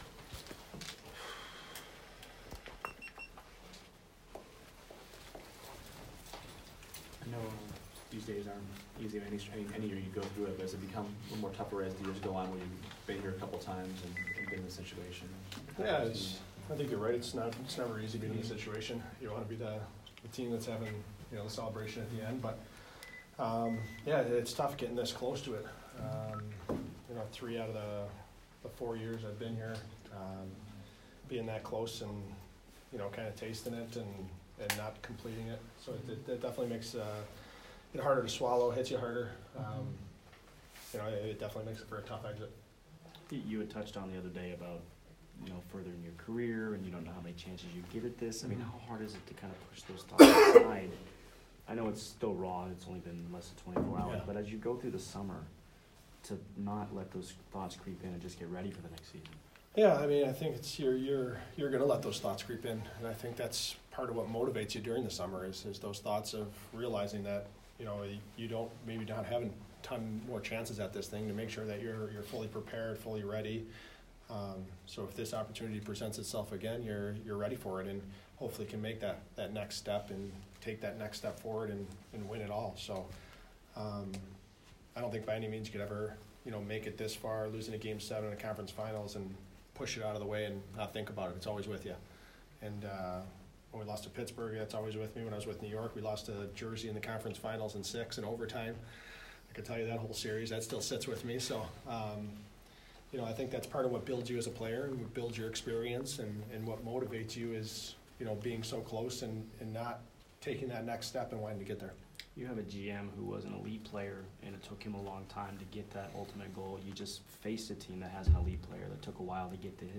Ryan Callahan Exit Interview 5/24